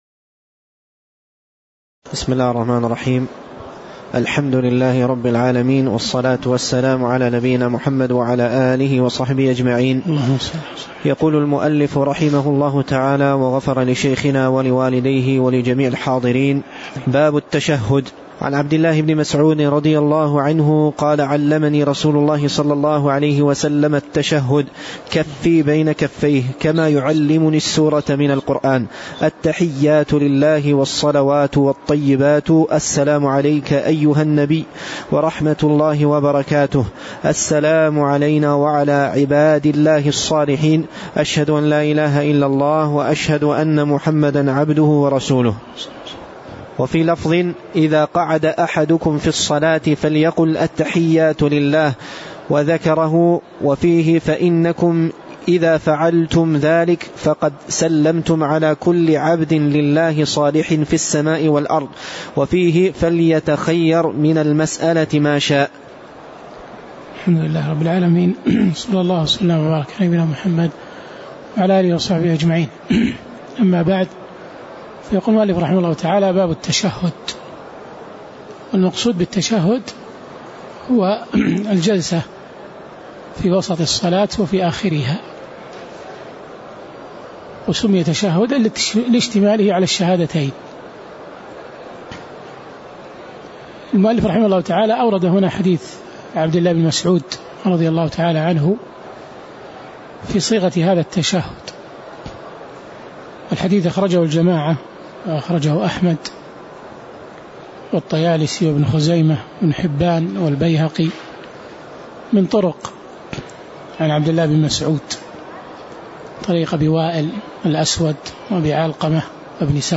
تاريخ النشر ٢٢ ربيع الثاني ١٤٣٧ هـ المكان: المسجد النبوي الشيخ